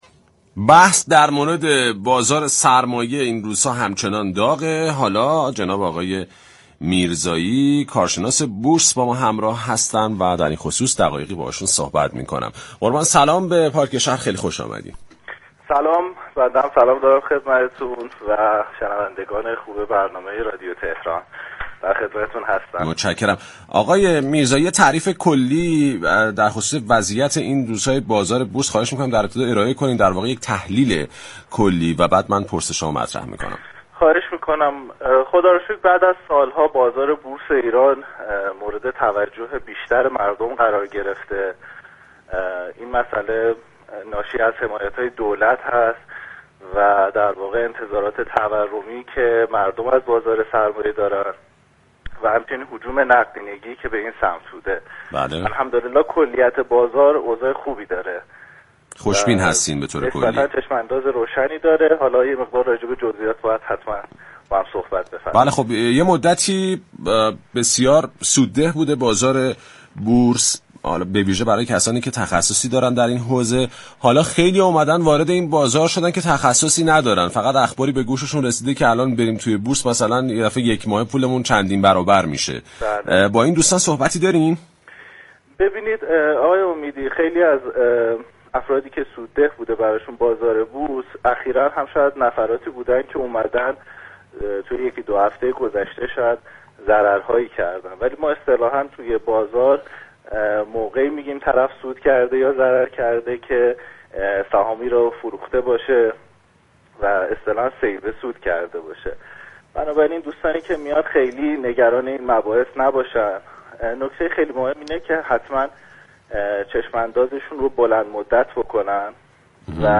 وی در گفتگو با "پارك‌شهر" در ارتباط با وضعیت امروز بازار بورس كشور گفت: خوشبختانه بعد از سال‌ها بازار بورس ایران مورد توجه بیشتر مردم قرار گرفته و مدیون توجه و حمایت دولت به این فعالیت اقتصادی است.